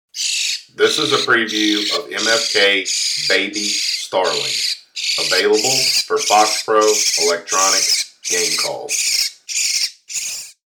MFK Baby Starling - 16 bit
Recorded with the best professional grade audio equipment MFK strives to produce the highest